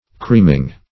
Creaming - definition of Creaming - synonyms, pronunciation, spelling from Free Dictionary